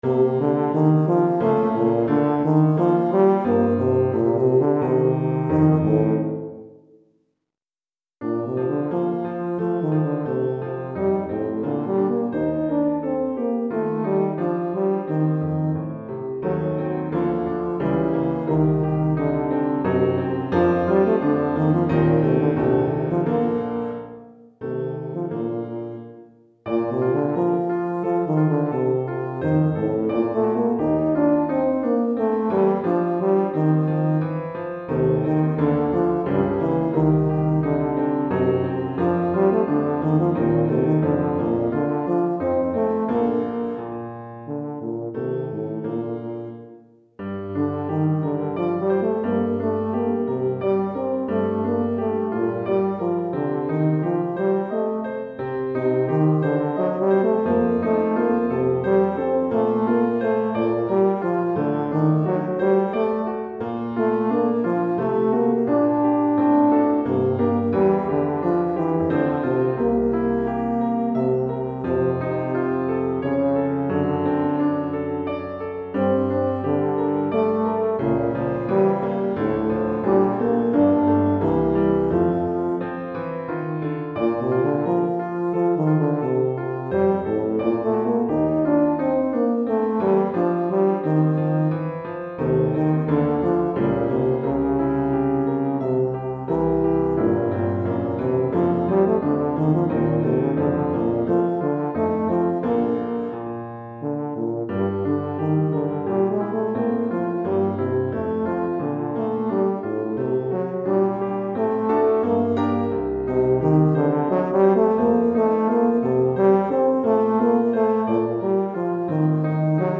Euphonium et Piano